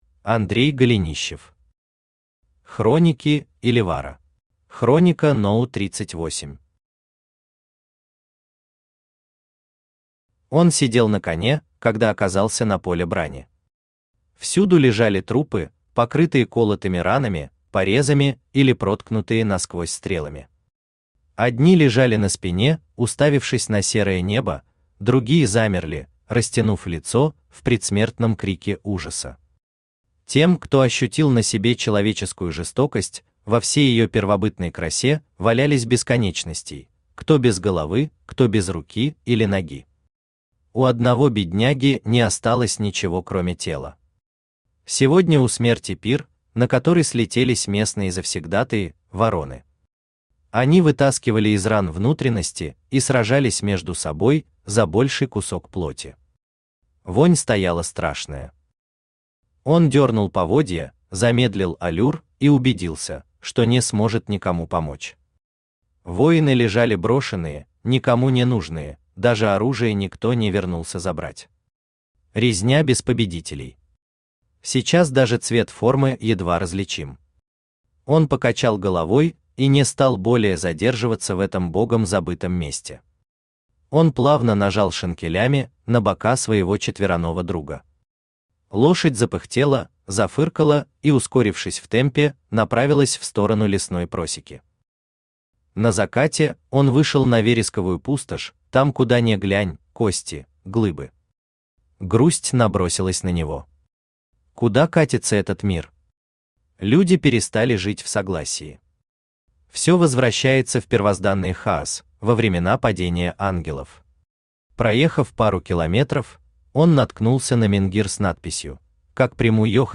Аудиокнига Хроники Иливара | Библиотека аудиокниг
Aудиокнига Хроники Иливара Автор Андрей Валерьевич Голенищев Читает аудиокнигу Авточтец ЛитРес.